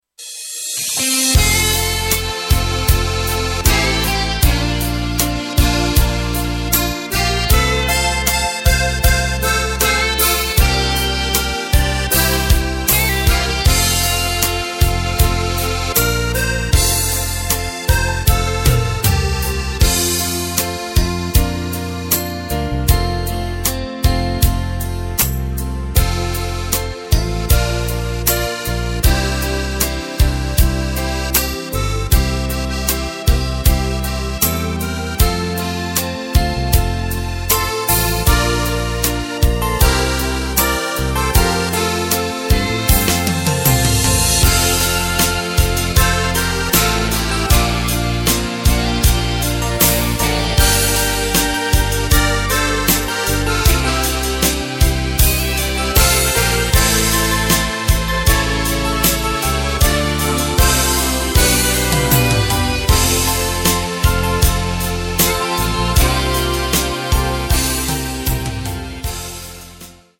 Tempo:         78.00
Tonart:            F
Schlager aus dem Jahr 2012!
Playback mp3 Demo